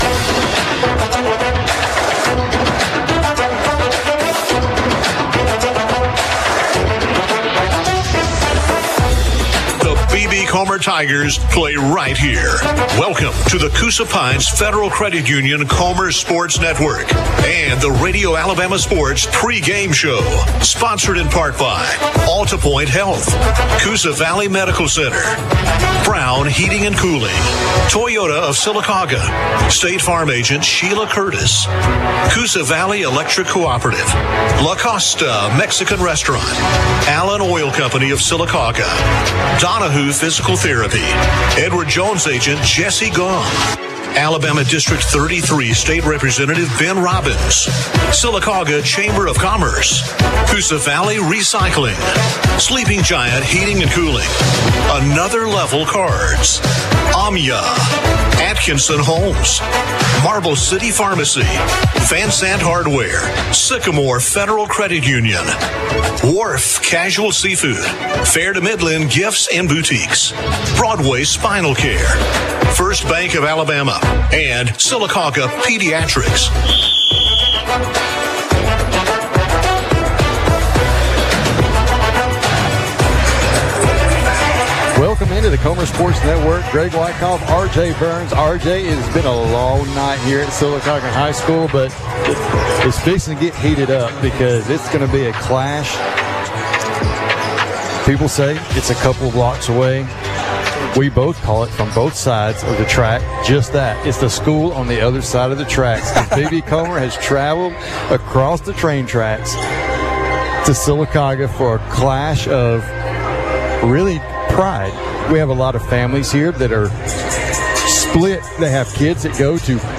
(Boys Basketball) Comer vs. Sylacauga